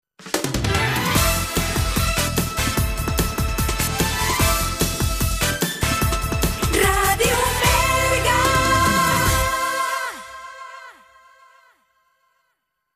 Dos indicatius amb la identificació de la ràdio.